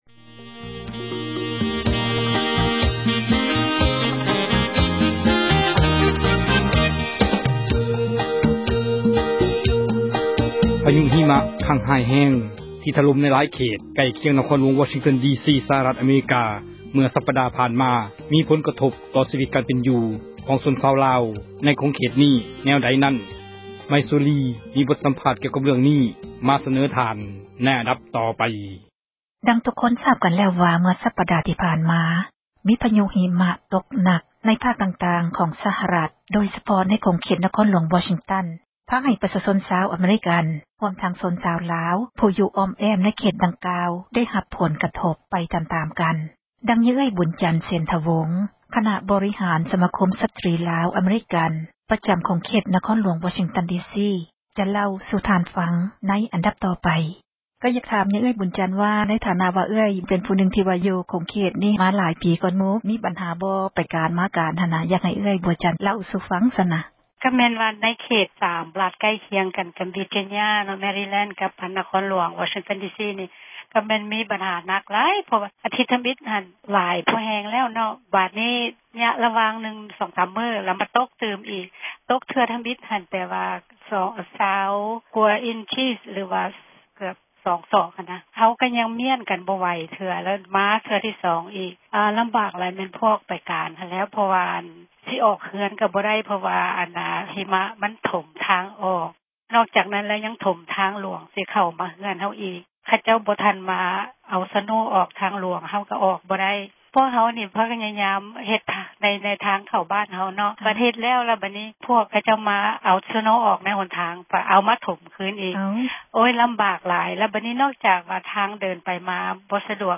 ສົນທະນາ